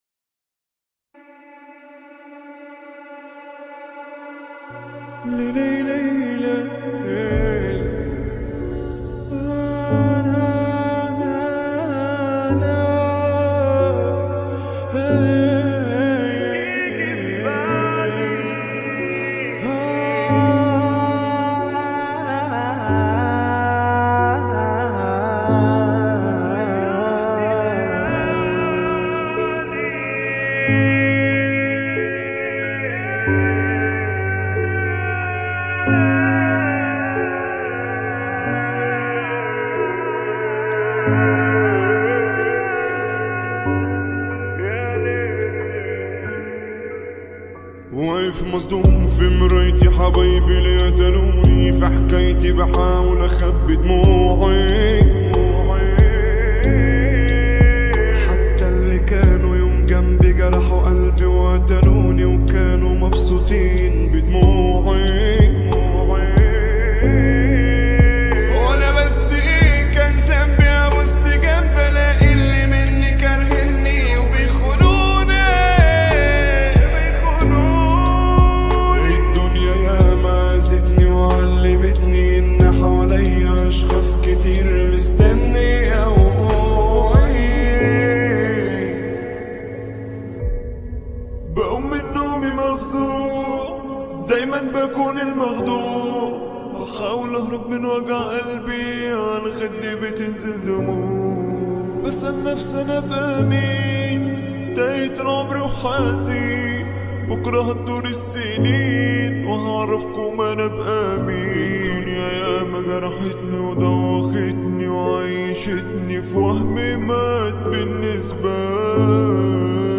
(Slowed + Reverb